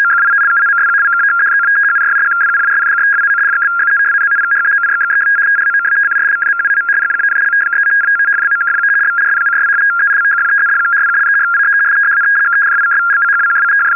FSK Systems - Frequency Shift Keying (F1B / F7B):
- CIS printer 75 Bd - 250 Hz shift